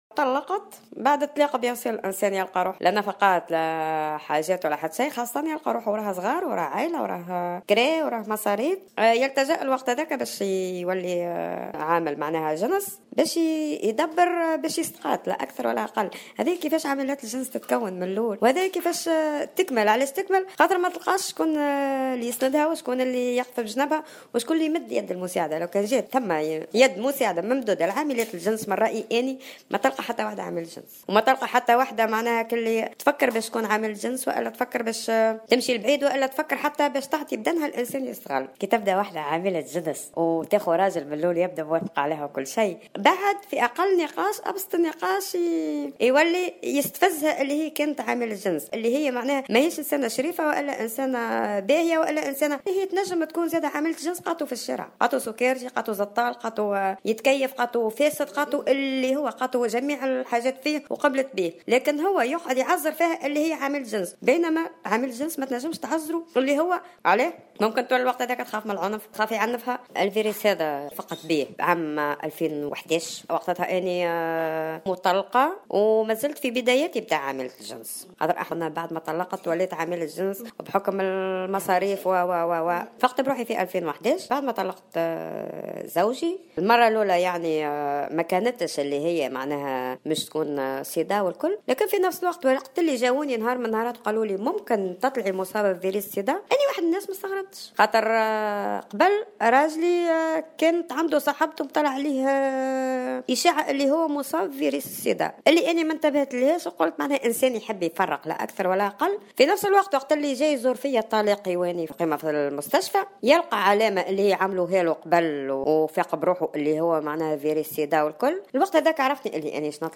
شهادات لمصابين بـ"الإيدز" في تونس : هكذا أصبنا بالعدوى وهذا ما ننصح به